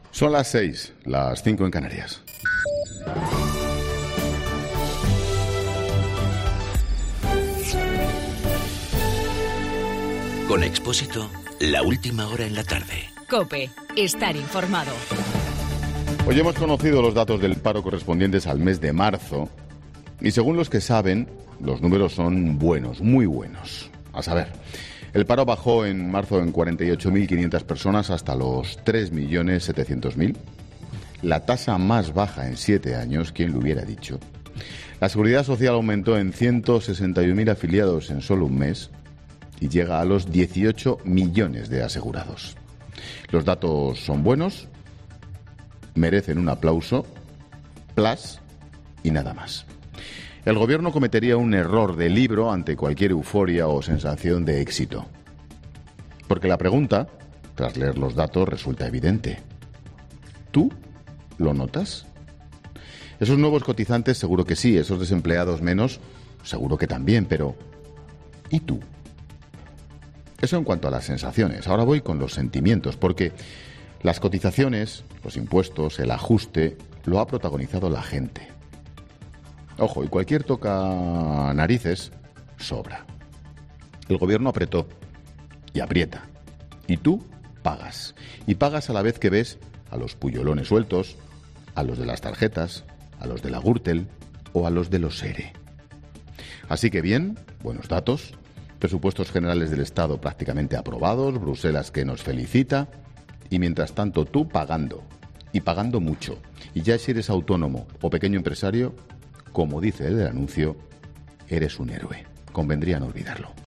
AUDIO: Monólogo 18 h.